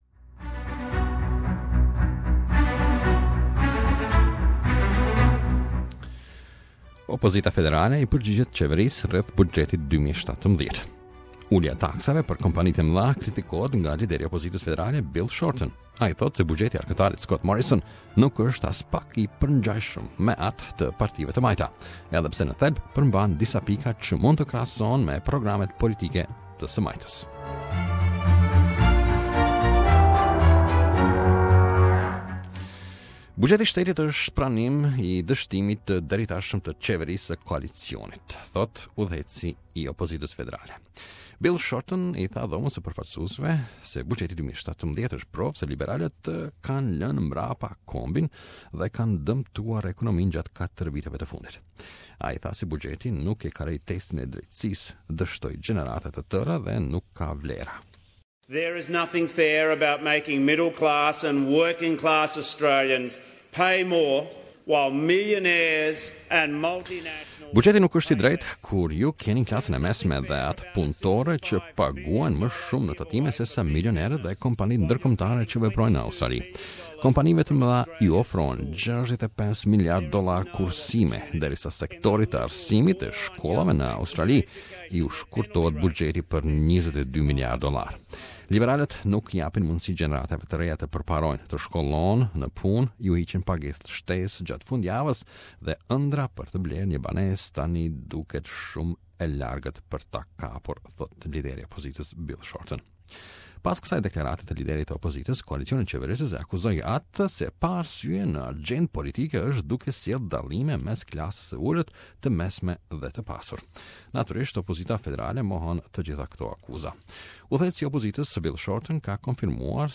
Opposition leader Bill Shorten delivers the 2017 Budget reply speech.